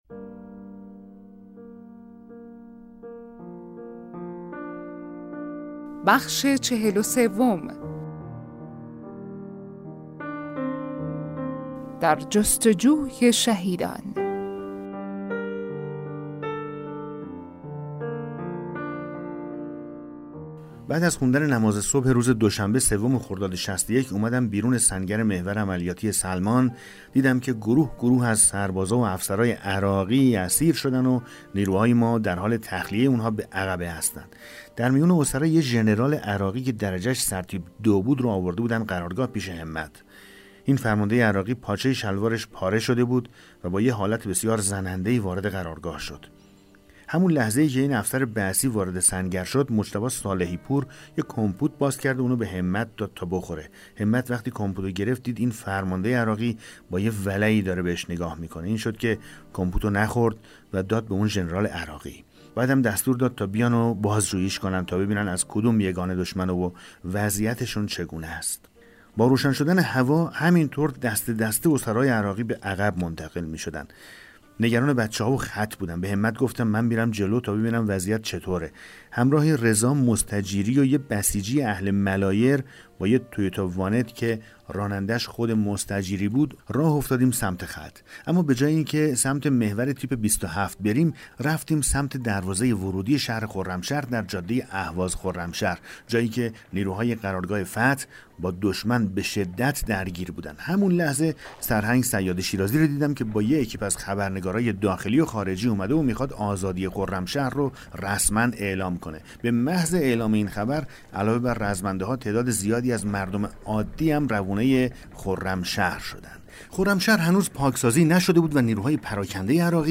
کتاب صوتی پیغام ماهی ها، سرگذشت جنگ‌های نامتقارن حاج حسین همدانی /قسمت 43